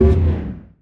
sci-fi_code_fail_09.wav